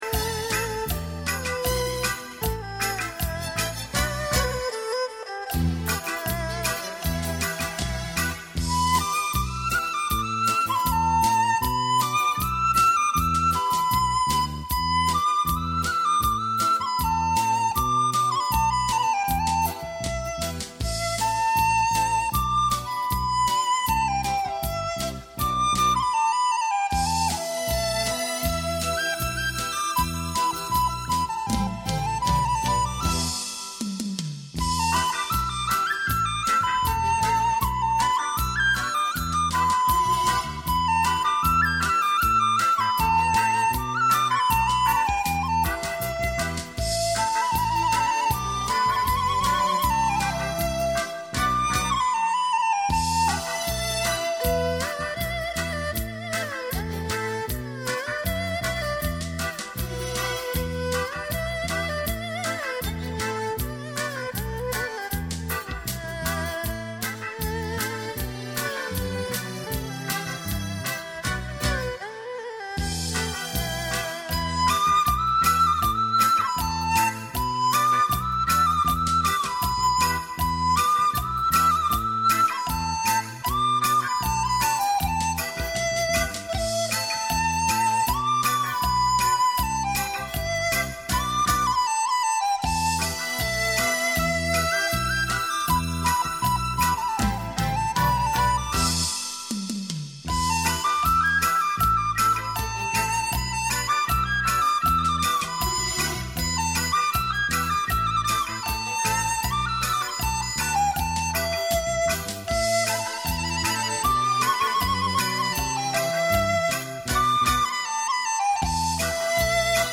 0279-康定情歌-笛子.mp3